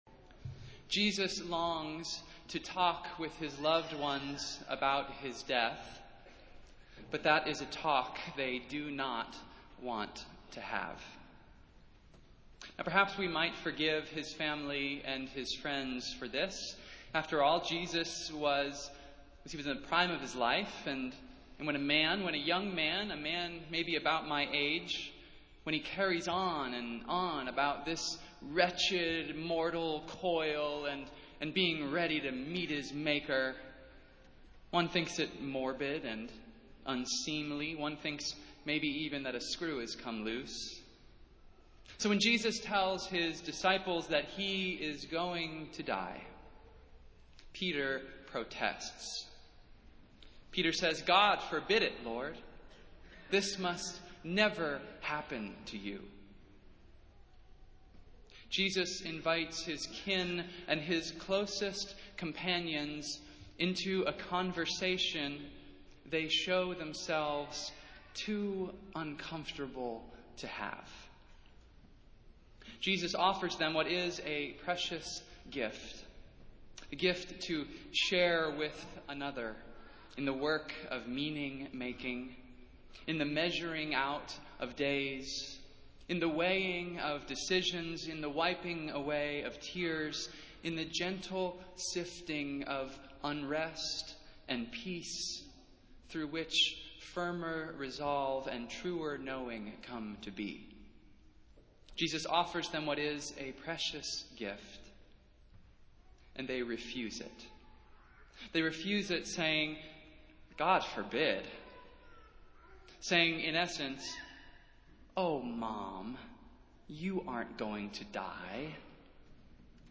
Festival Worship - Twenty-fourth Sunday after Pentecost